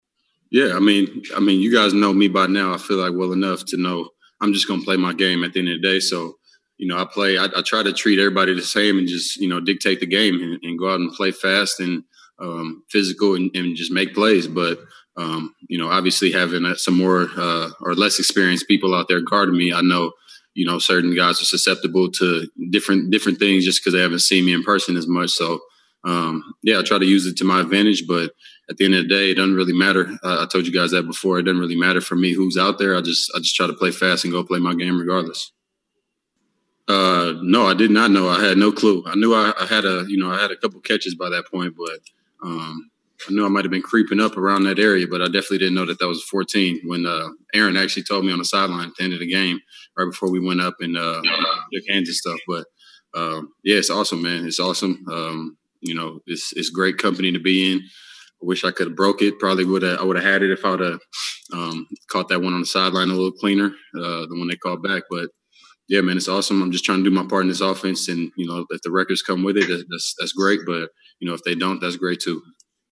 Afterwards, the Packers paraded LaFleur and three players before the zoom camera for post-game press conferences.